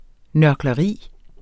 Udtale [ nɶɐ̯glʌˈʁiˀ ]